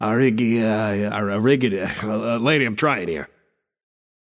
Shopkeeper voice line - Arigiyuhh… ari-arigata. Lady, I’m trying here.